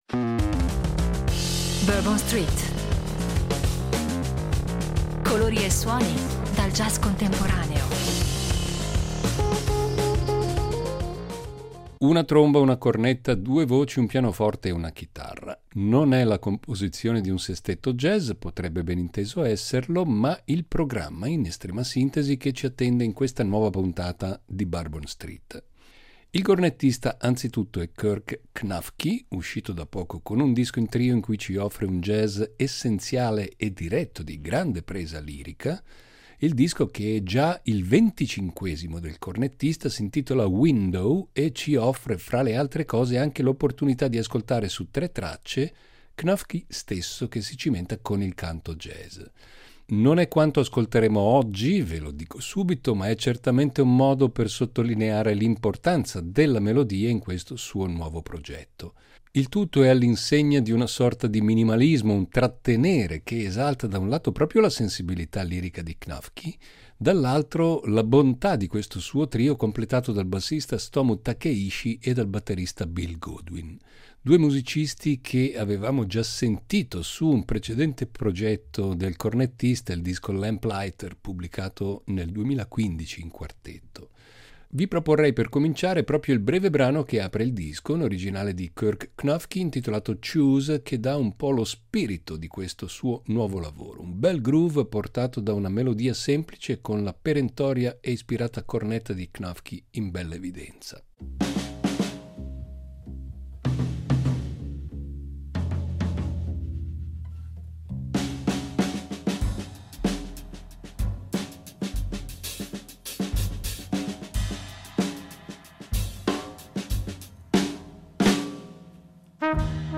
Un inizio d’anno all’insegna del jazz
Una tromba, una cornetta, due voci, un pianoforte e una chitarra.